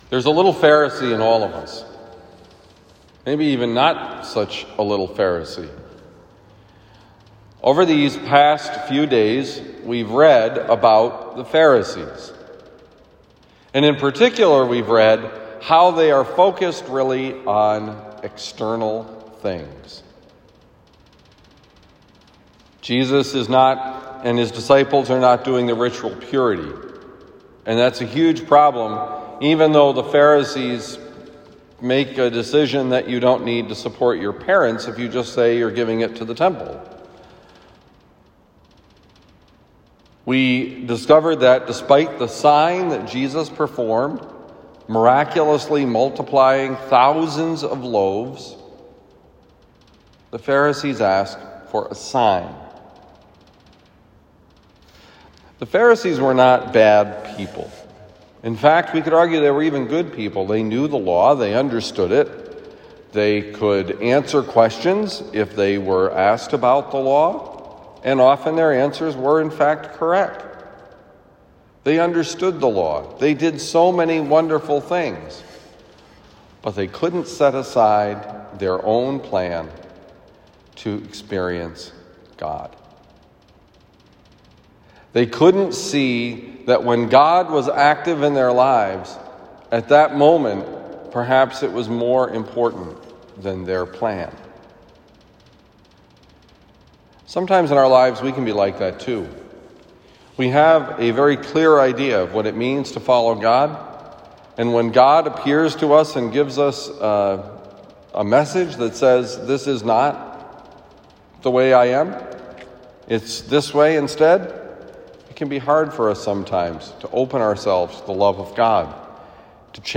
Given at Christian Brothers College High School, Town and Country, Missouri.